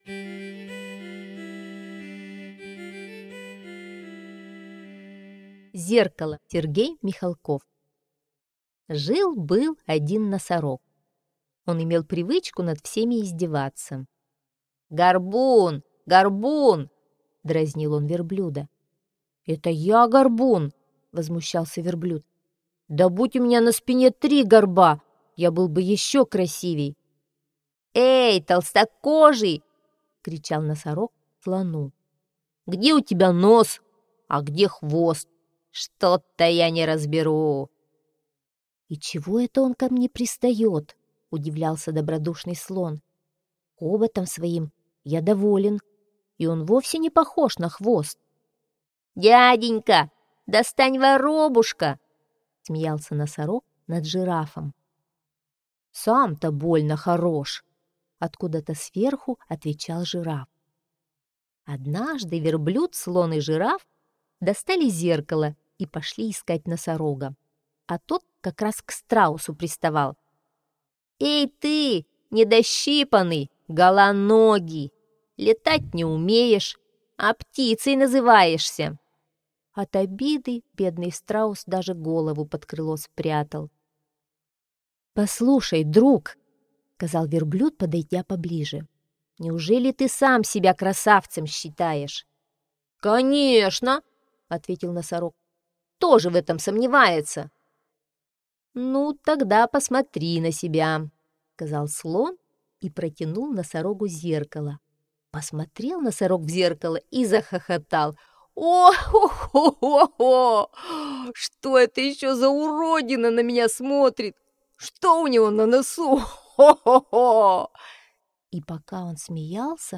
Зеркало – Михалков С.В. (аудиоверсия)